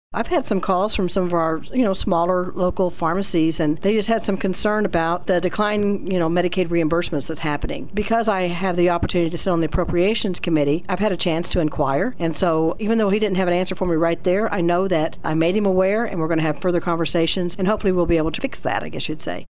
State senator Karla Eslinger says she is hearing concerns about local pharmacies.